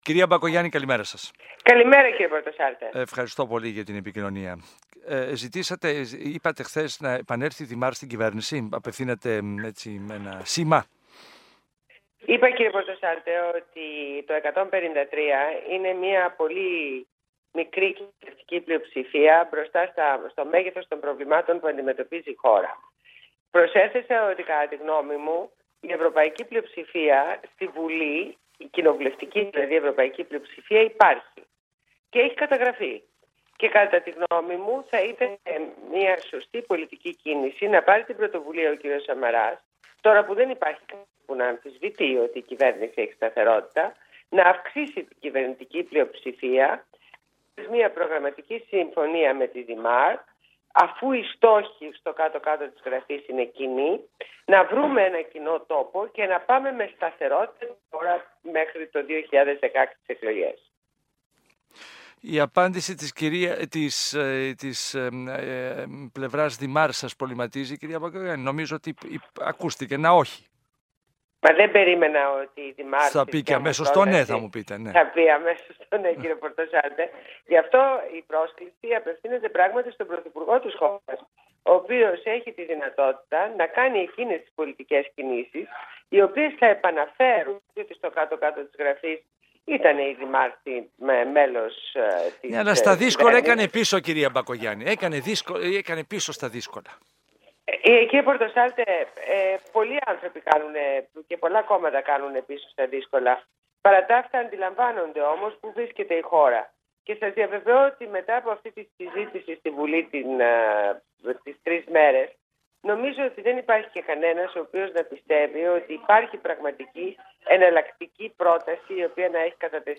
Συνέντευξη στο ραδιόφωνο του ΣΚΑΙ, στον Α. Πορτοσάλτε.